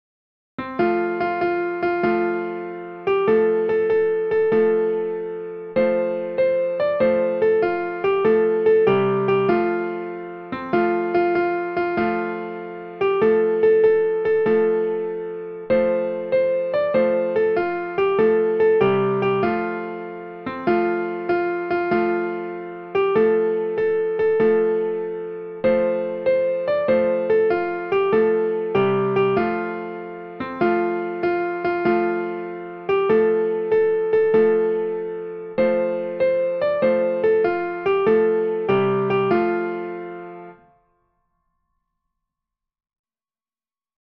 a children's song and dance
for piano